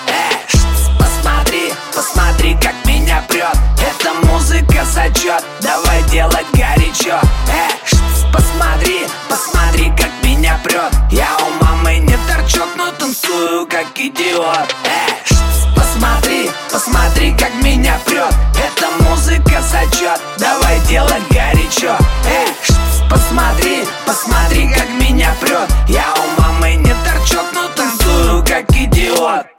• Качество: 128, Stereo
Хип-хоп